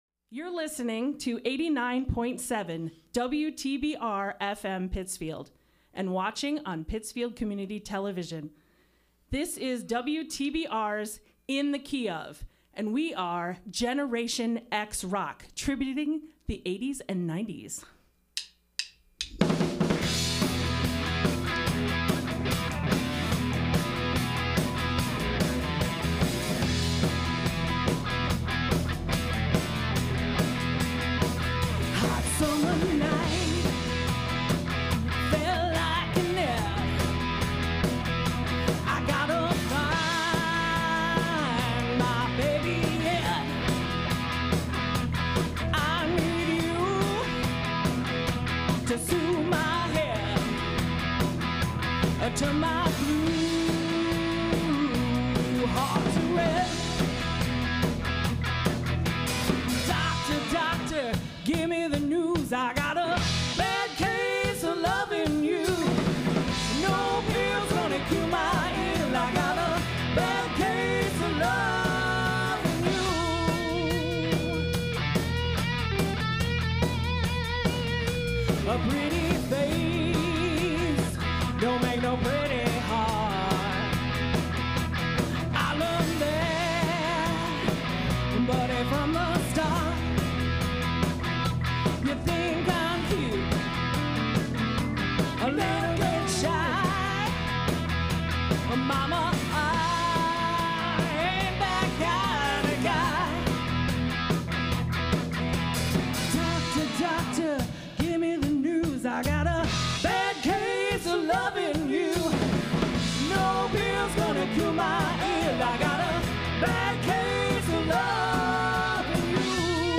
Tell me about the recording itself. Recorded live the PCTV / WTBR Studio